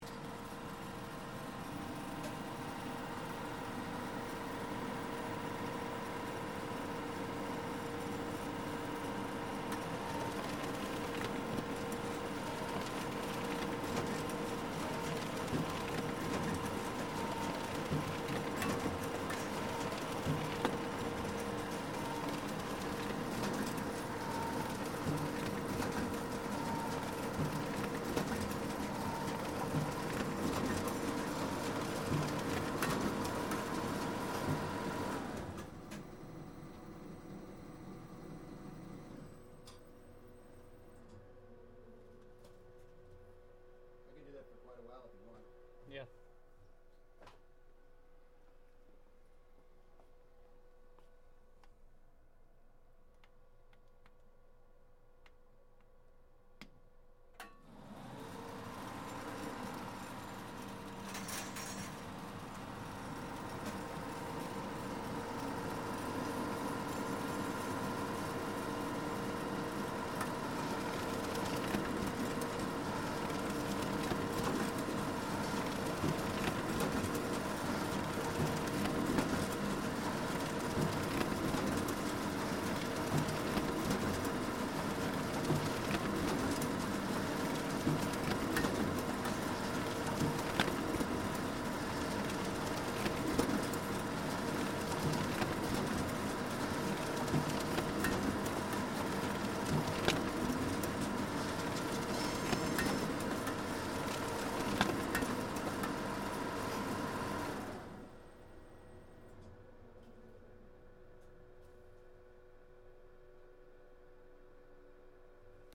Printing press machine